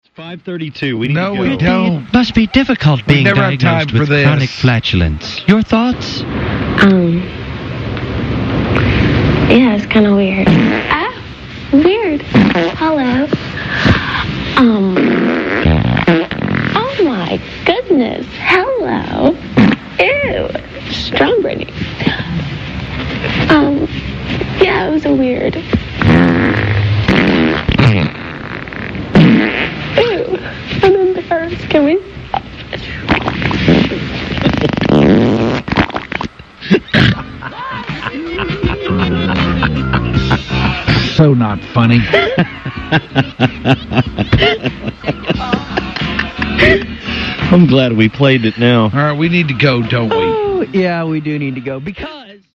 Britney Spears Fart Interview
Britney Spears blows out this interview
britney-spears-farts.mp3